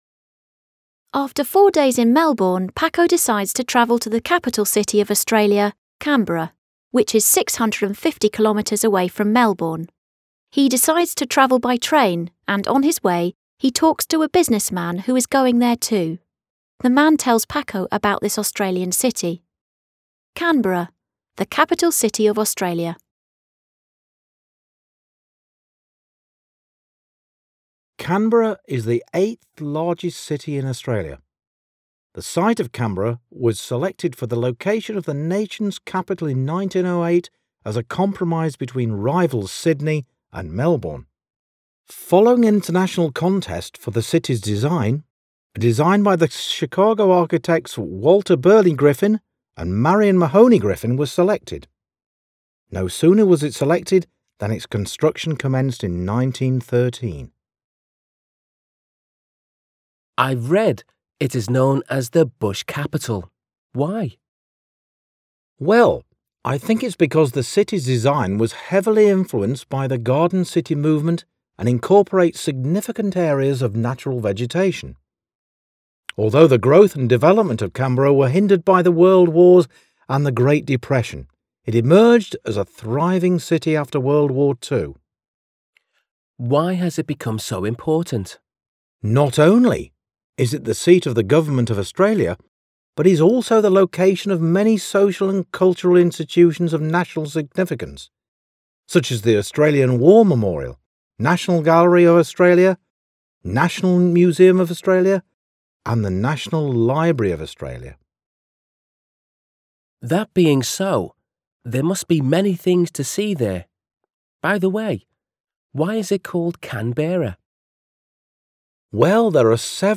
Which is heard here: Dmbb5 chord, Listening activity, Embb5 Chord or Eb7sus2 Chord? Listening activity